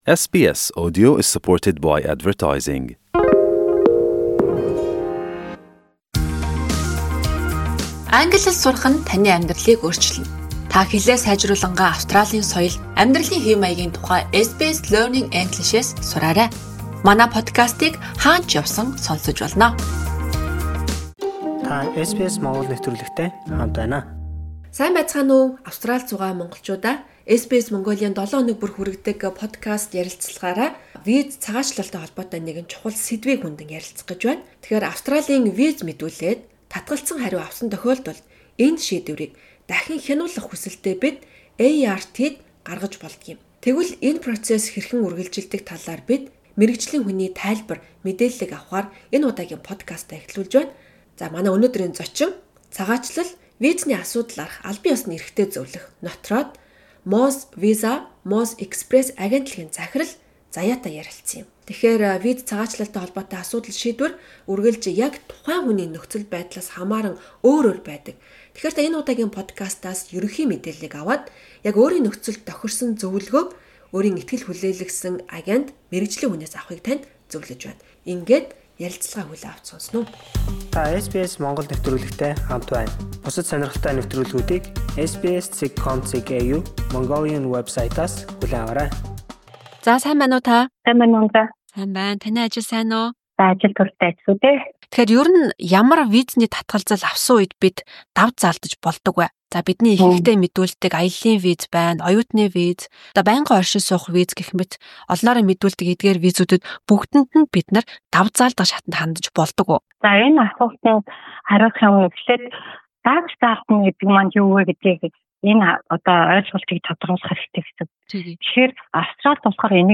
Цагаачлал, визний асуудлаарх албан ёсны эрхтэй зөвлөх